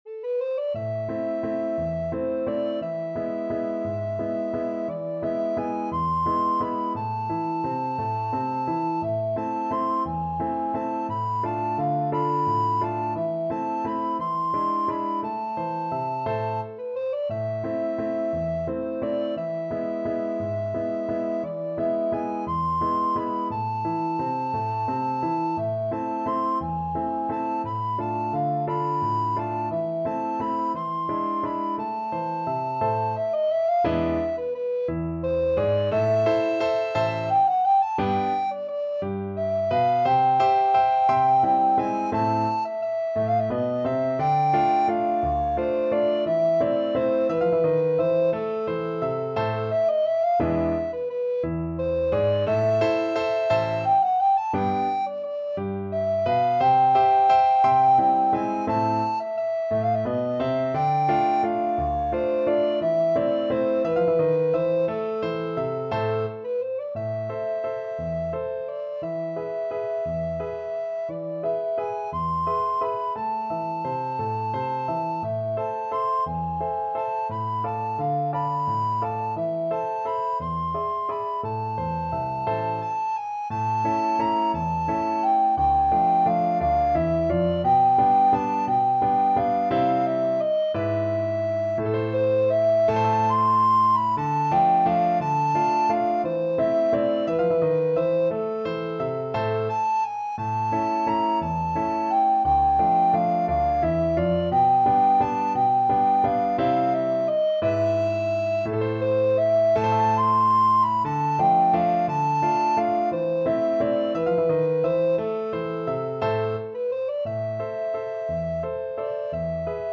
A minor (Sounding Pitch) (View more A minor Music for Alto Recorder )
3/4 (View more 3/4 Music)
One in a bar . = 58
Alto Recorder  (View more Intermediate Alto Recorder Music)
Classical (View more Classical Alto Recorder Music)